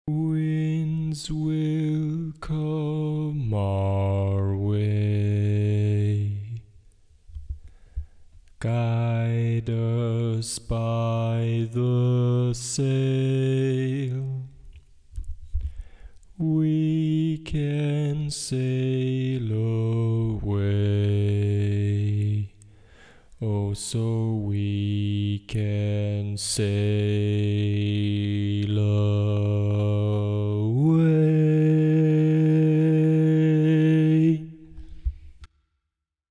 Key written in: E♭ Major
Type: Barbershop
Comments: Original tag, ballad-style barbershop
Each recording below is single part only.